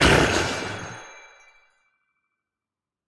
Minecraft Version Minecraft Version 25w18a Latest Release | Latest Snapshot 25w18a / assets / minecraft / sounds / mob / glow_squid / death1.ogg Compare With Compare With Latest Release | Latest Snapshot